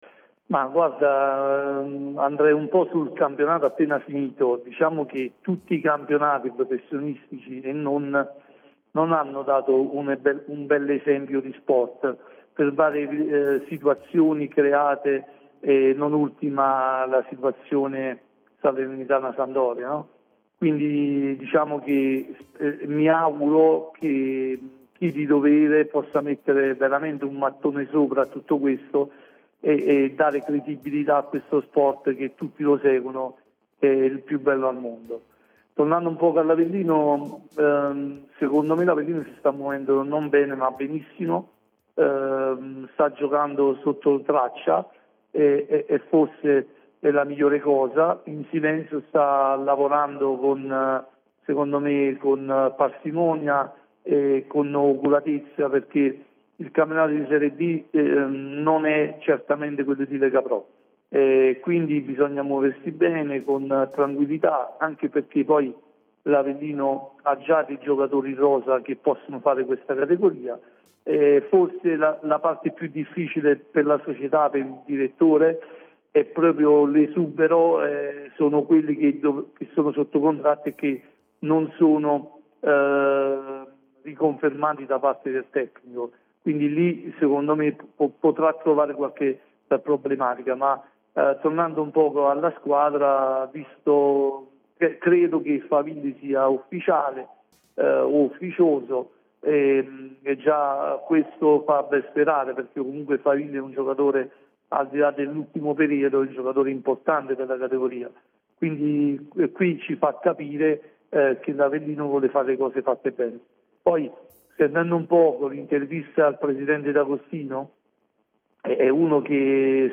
Nel corso dell’appuntamento odierno di Punto Nuovo Sport